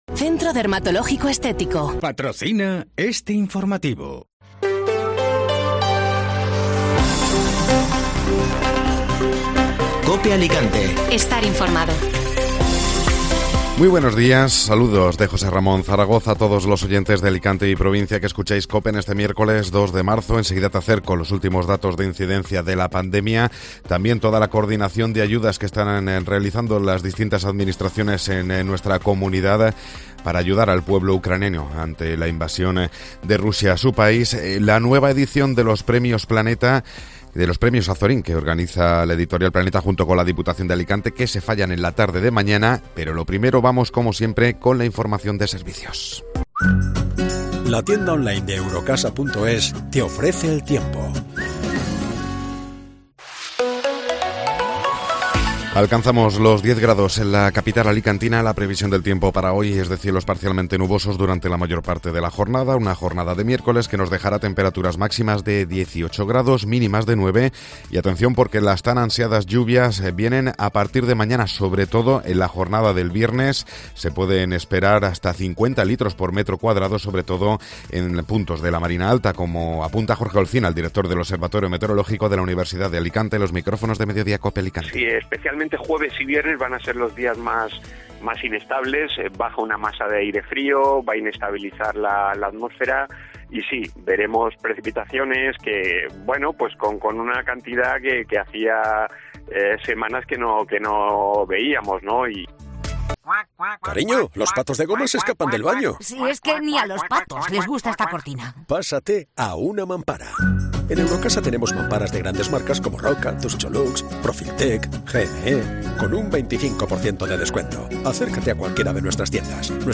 Informativo Matinal (Miércoles 2 de Marzo)